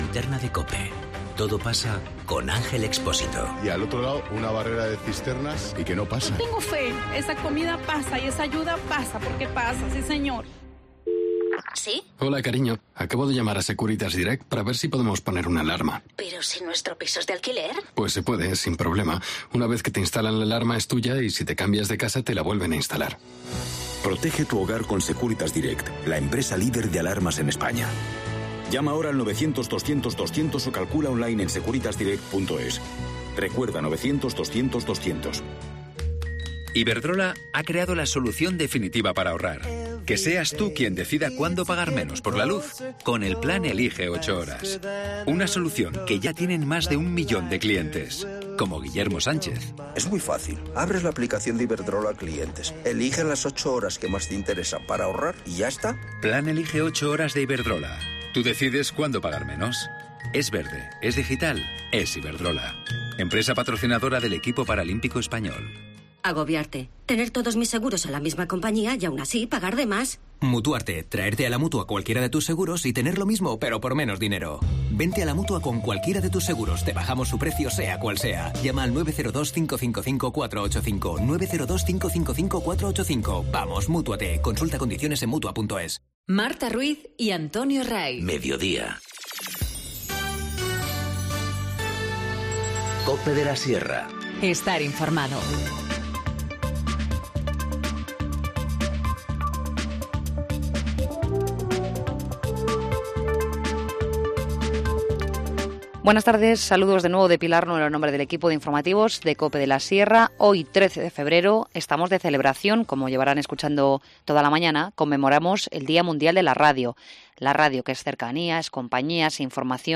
Informativo Mediodía 13 febrero- 14:50h